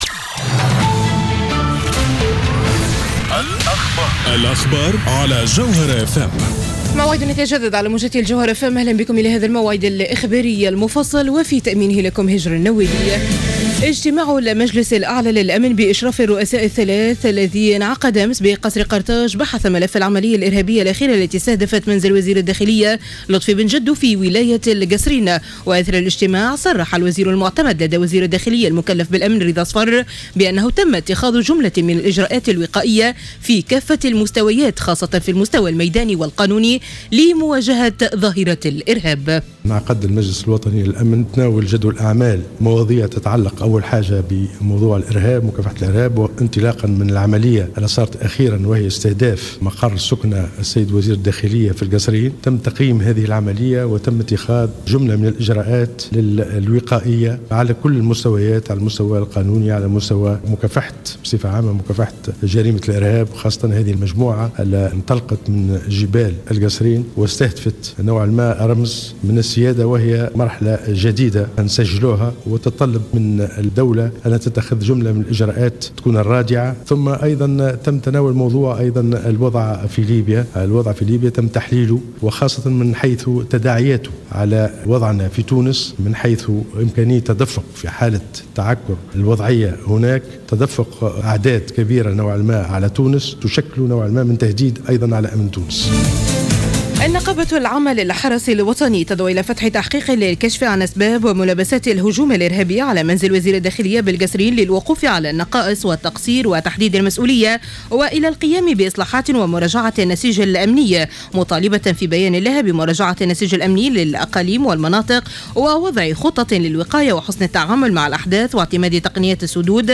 Journal Info 00h00 du 30-05-14